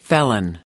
/fel´ǝn/